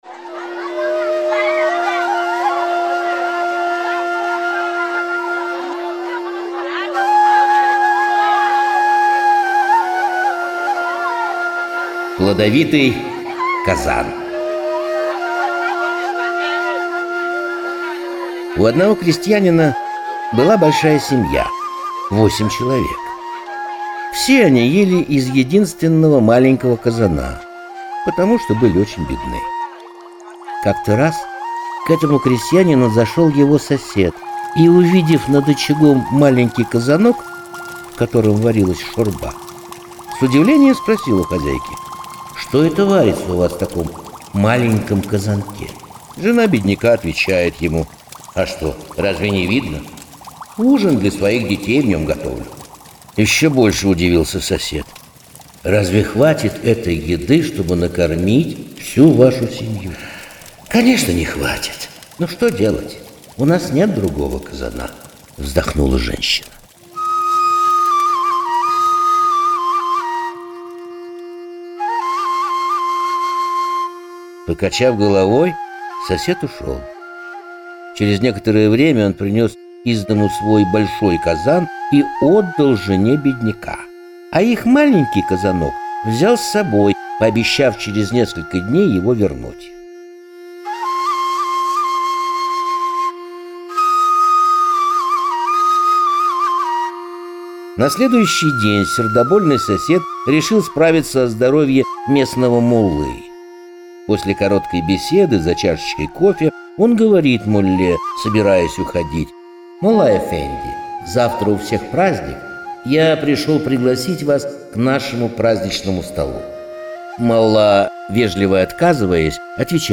Плодовитый казан - крымскотатарская аудиосказка - слушать онлайн